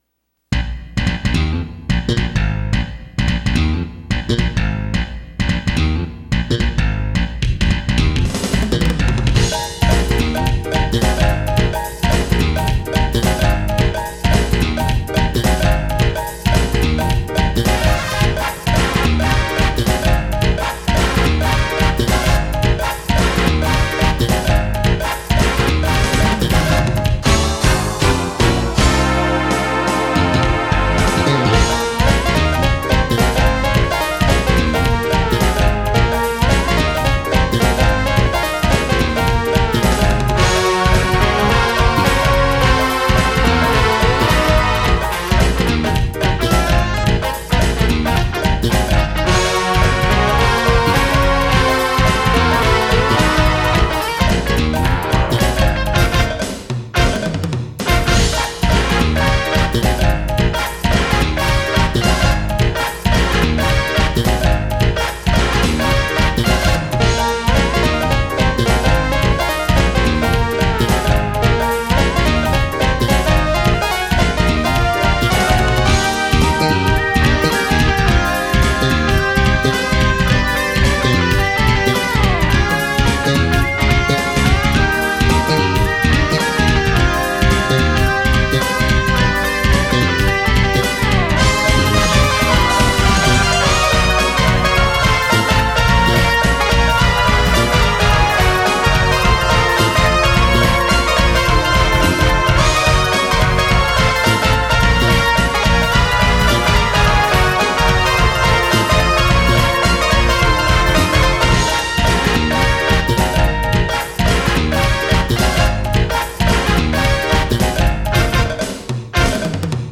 なお、掲載している音源には、EQとコンプレッサー等で若干のマスタリング処理を施してあります。
ともあれこの曲では、アレンジ面での平板さの傾向が若干抑えられており、生演奏を感じさせるフレージングを用いながら、スペース（空間）や響きの厚さにメリハリをつけようとしている様子が伺えます。